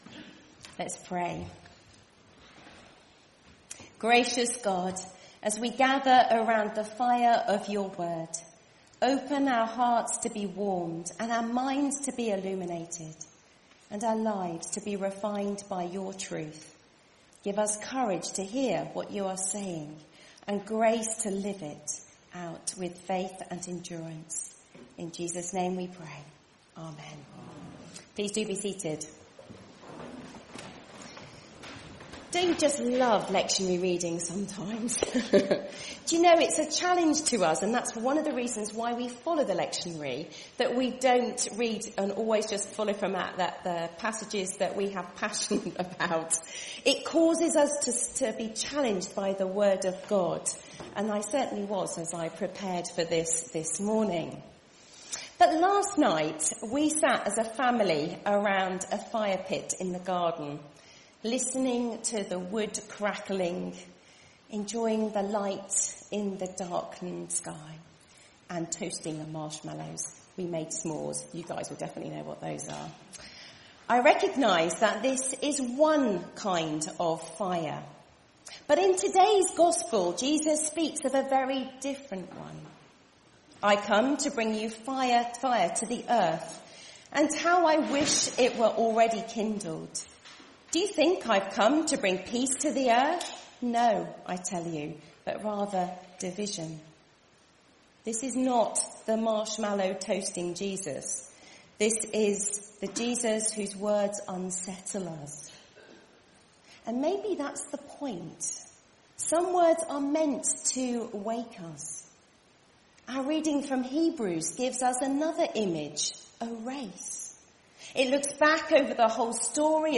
This is the Gospel of the Lord All Praise to you, O Christ Series: Ordinary Time , Sunday Morning